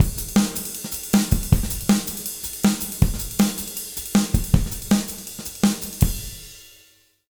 160JUNGLE3-R.wav